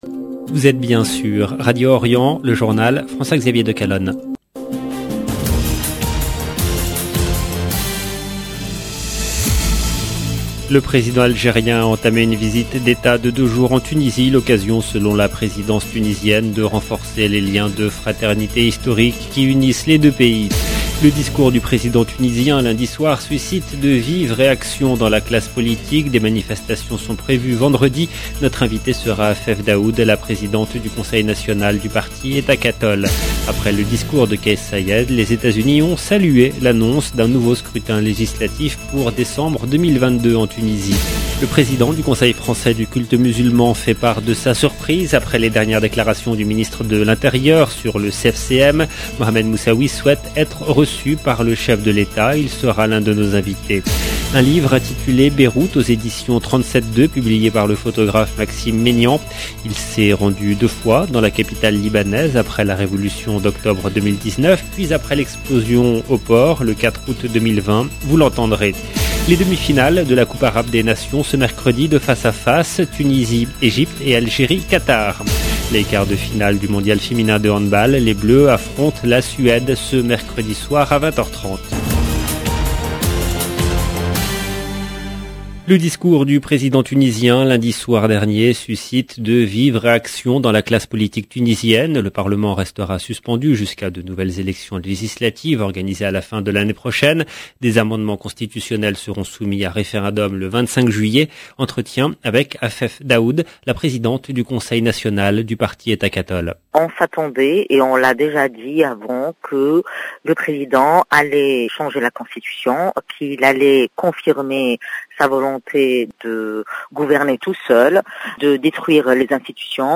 LB JOURNAL EN LANGUE FRANÇAISE Journal présenté par